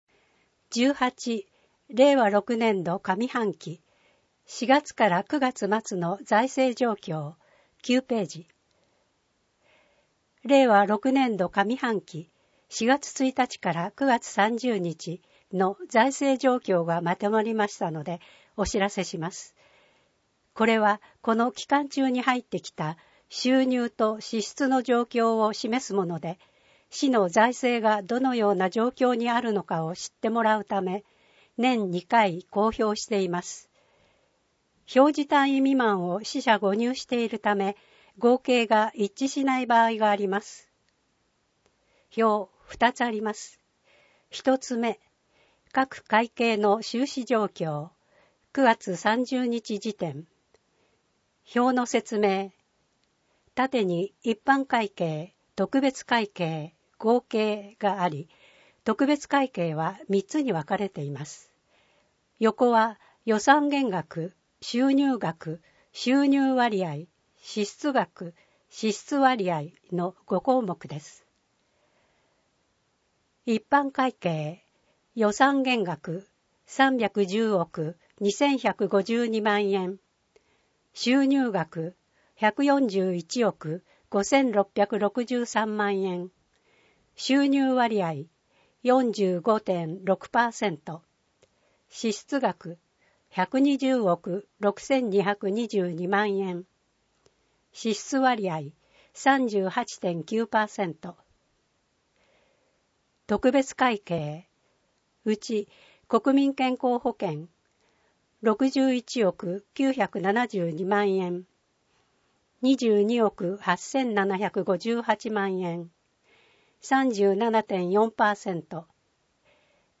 目の不自由な人などのために録音されたデイジー図書を掲載しています。